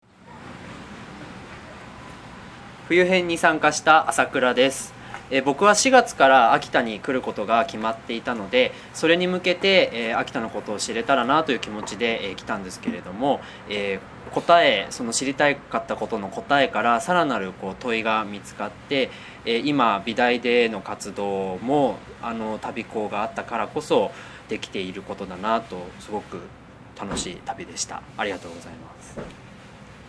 昨年度の「旅する地域考」参加者から いまも印象に残ることなどを 30 秒でコメントいただきました。